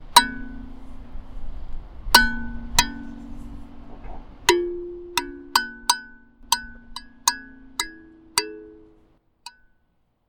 우주_칼림바2.mp3